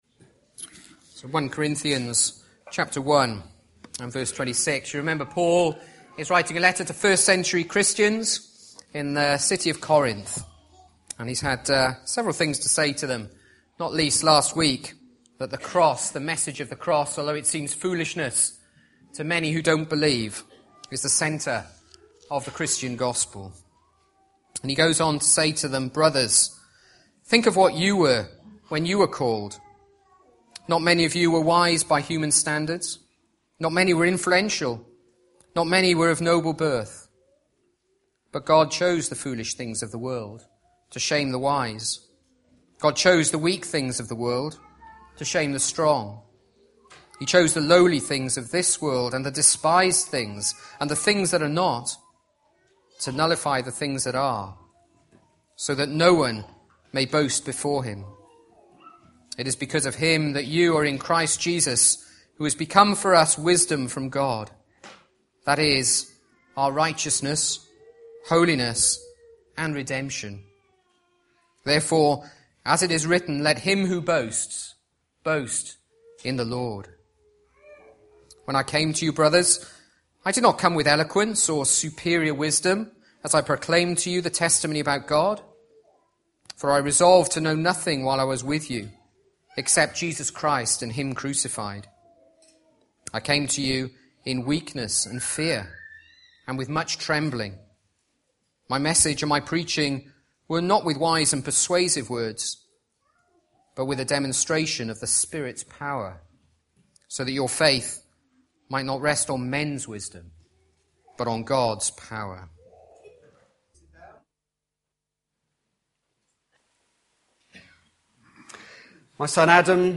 Back to Sermons Boast in the Lord